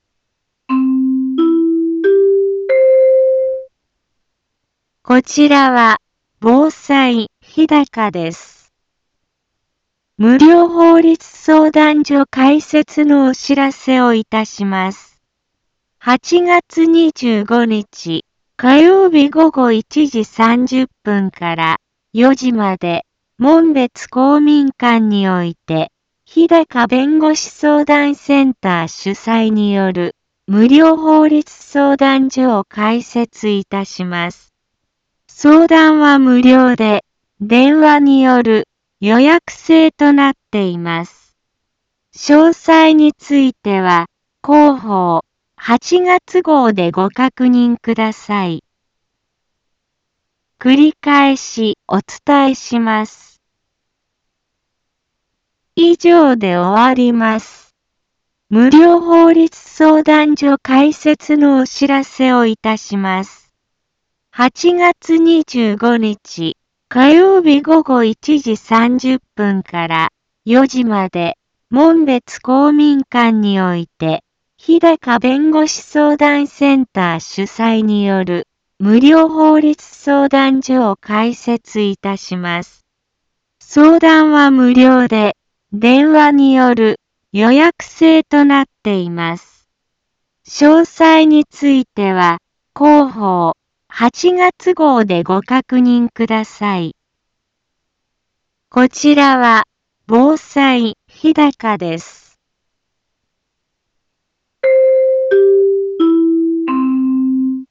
一般放送情報
Back Home 一般放送情報 音声放送 再生 一般放送情報 登録日時：2020-08-21 10:03:47 タイトル：法律相談所 インフォメーション：無料法律相談所開設のお知らせをいたします。